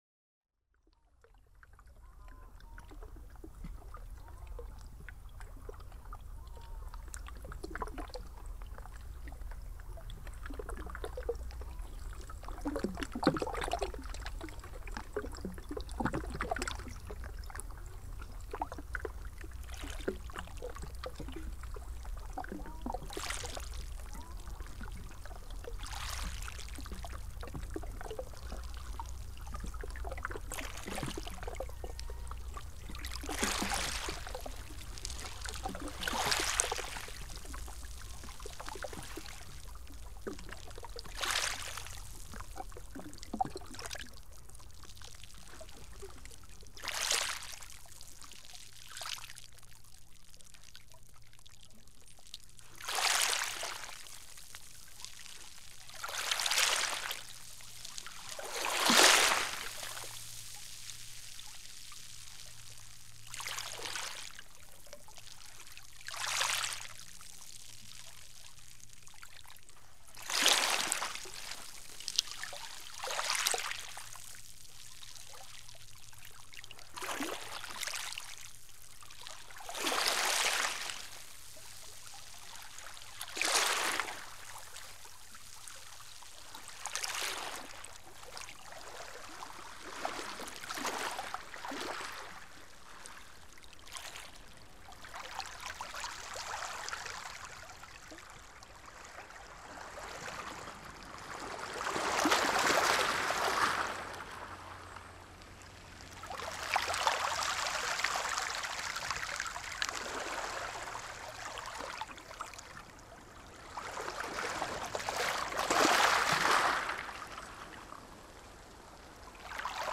Sounds of the Salish Sea
Sounds-of-the-Salish-Sea.mp3